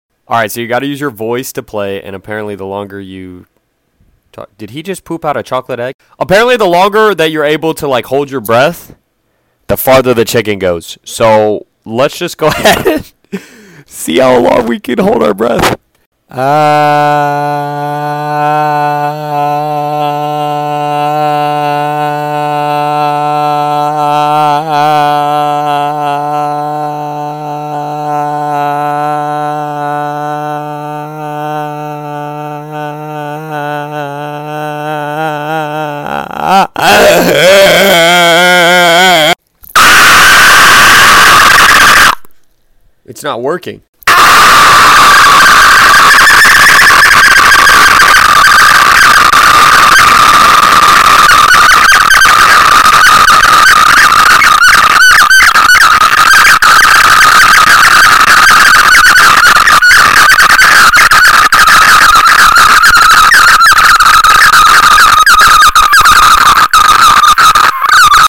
My scream broke the sound sound effects free download